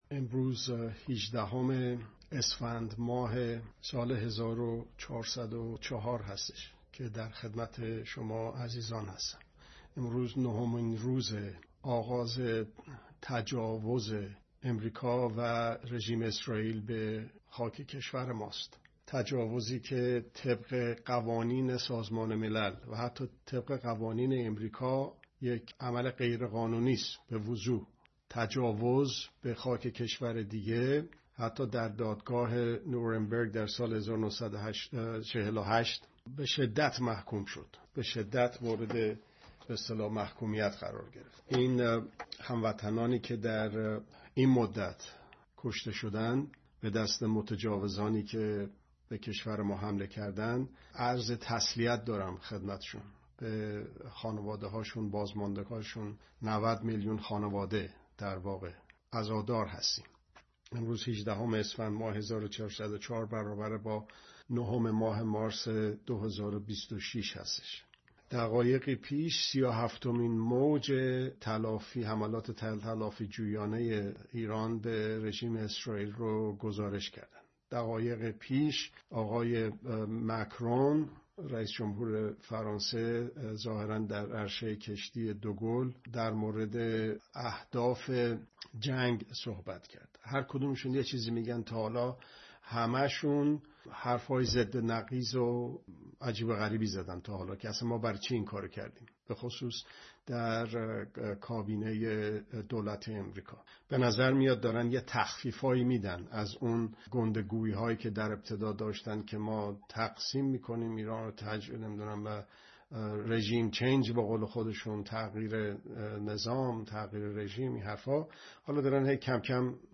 ( دوشنبه‌ها ساعت۲۱ به‌وقت ایران به‌صورت زنده )